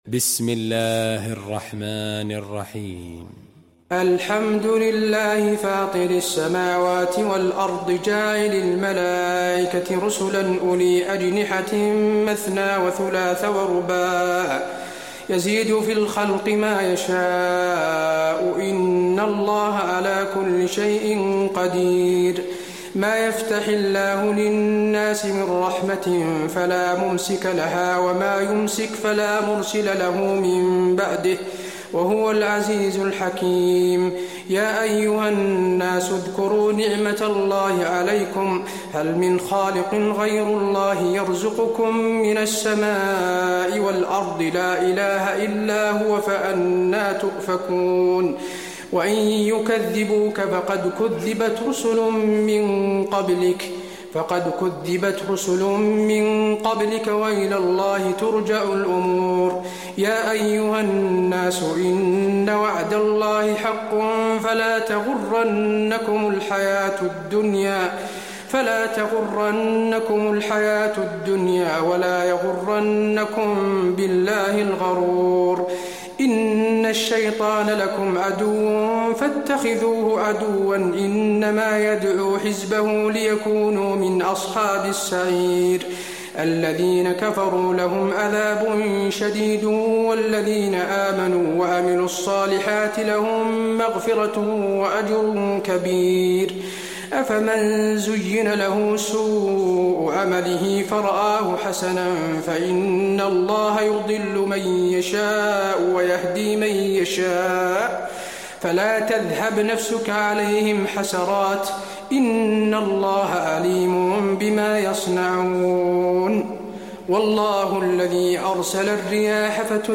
المكان: المسجد النبوي فاطر